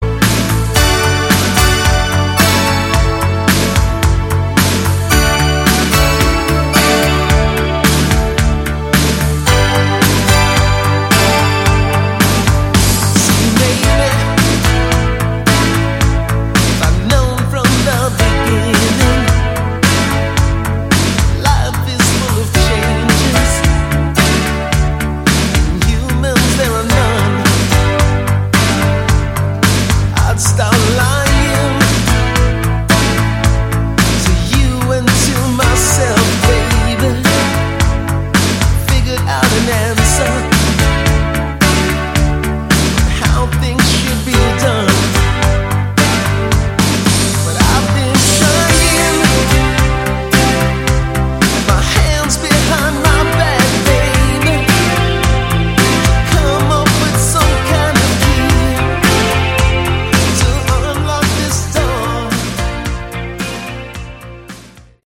Category: Hard Rock
vocals, guitar, keyboards, bass
drums